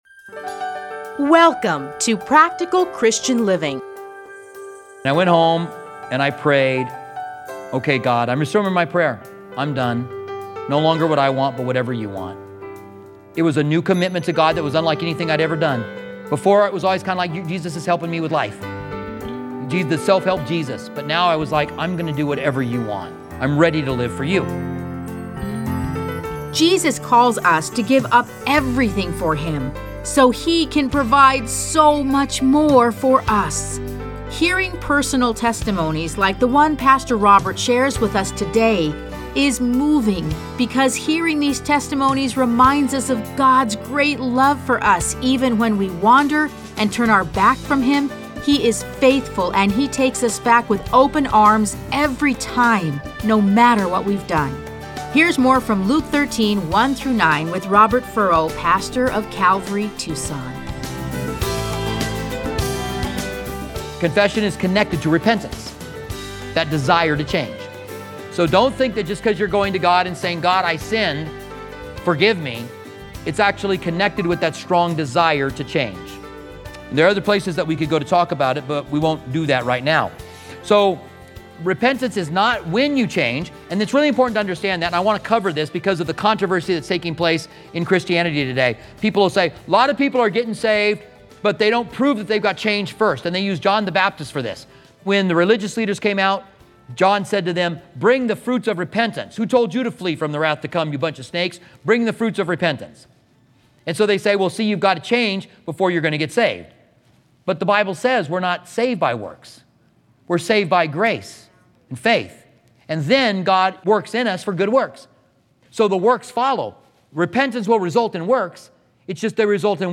Listen to a teaching from Luke 13:1-9.